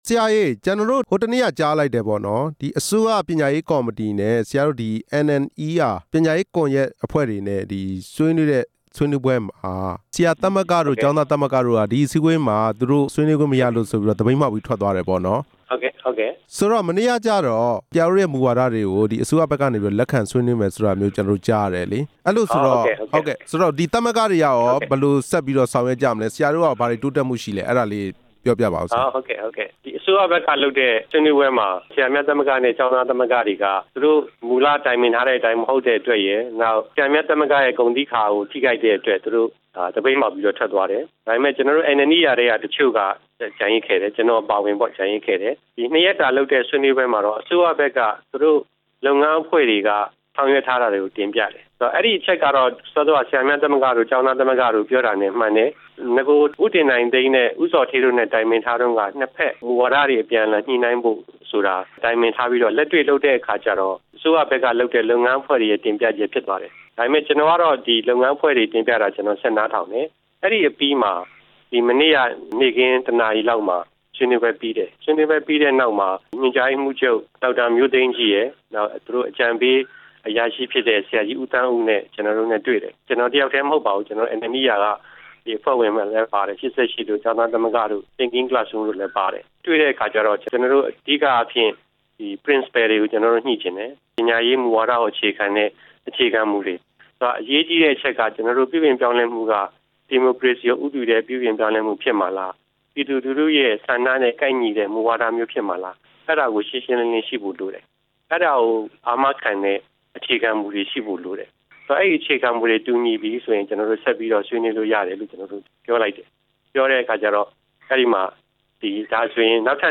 ပညာရေးမူဝါဒ ဆွေးနွေးမယ့်အကြောင်း NNER အဖွဲ့နဲ့ မေးမြန်းချက်